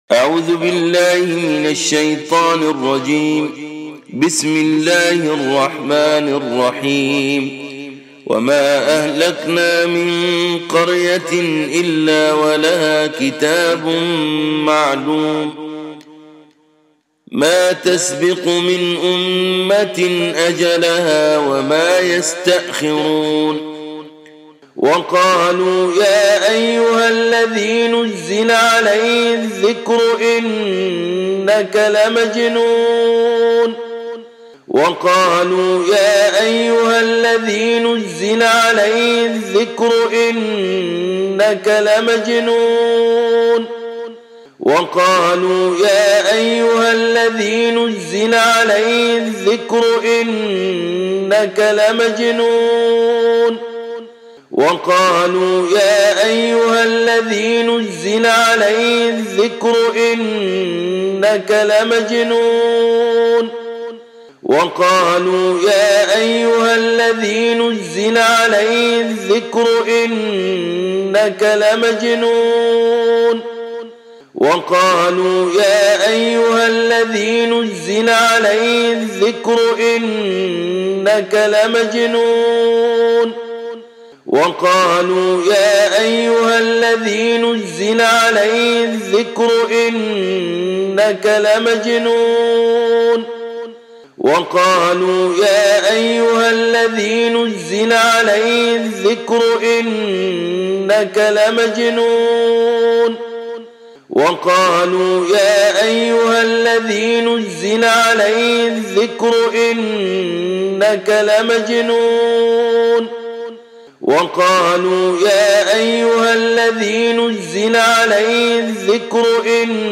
রুকইয়াহ অডিও